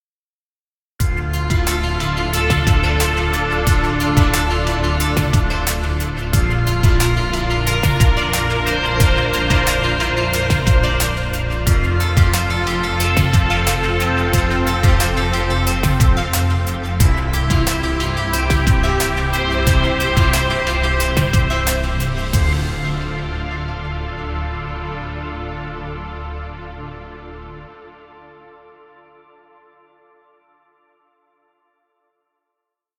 Chillout music.